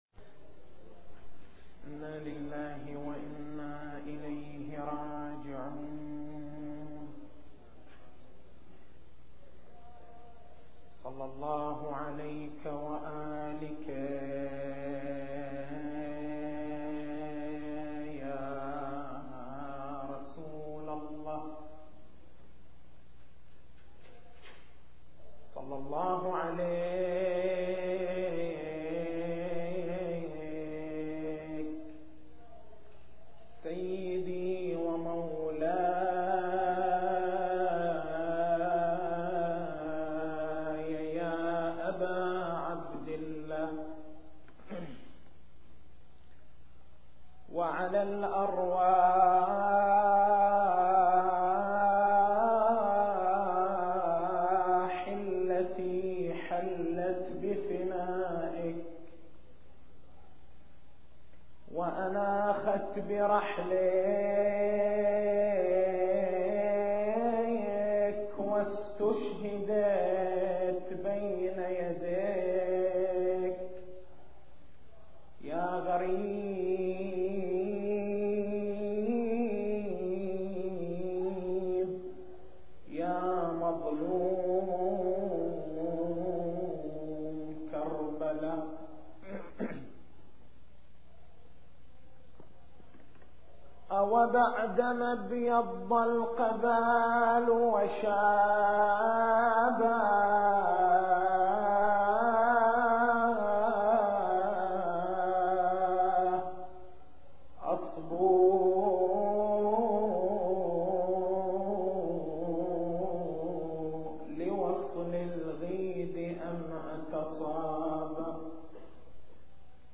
تاريخ المحاضرة: 03/01/1424 نقاط البحث: نشأة الكيان الشيعي أثر النهضة الحسينية في حركة التشيع التسجيل الصوتي: تحميل التسجيل الصوتي: شبكة الضياء > مكتبة المحاضرات > محرم الحرام > محرم الحرام 1424